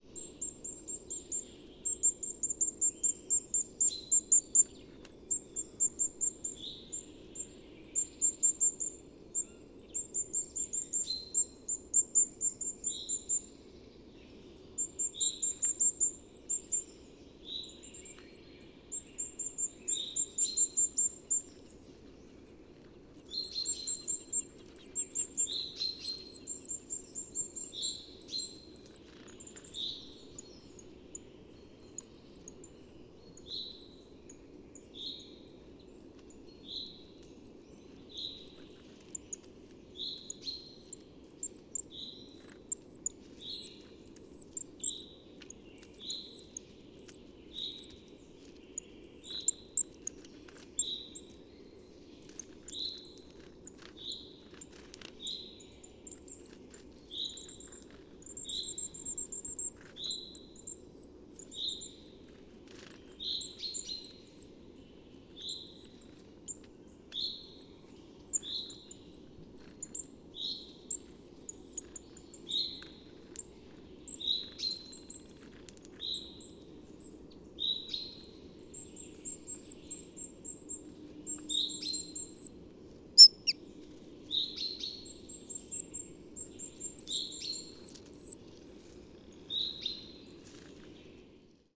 PFR10680, 1-10, 150324, Goldcrest Regulus regulus, social call, excitement call, flight call
north west saxony, Germany, Telinga parabolic reflector